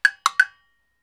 • short wooden block sample.wav
Recorded in a professional studio with a Tascam DR 40 linear PCM recorder.
short_wooden_block_sample_rsZ.wav